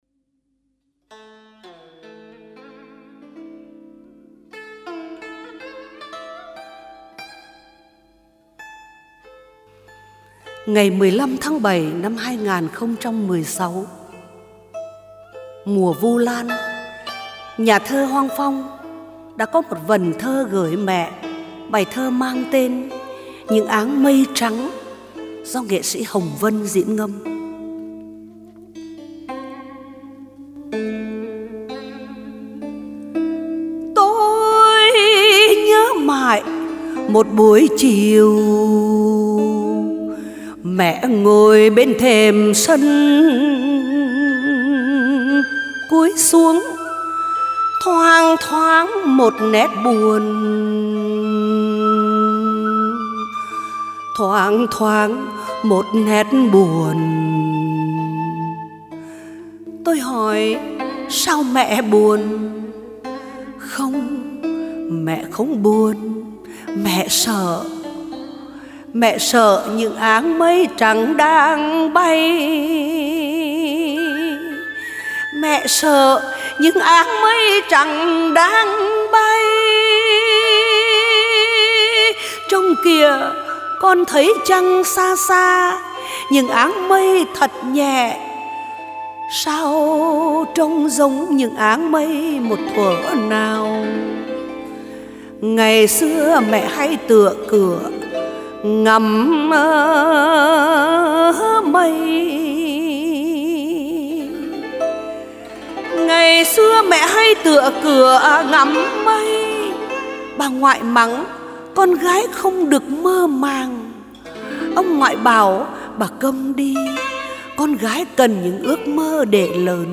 Những áng mây trắng Thơ: Hoang Phong Diễn ngâm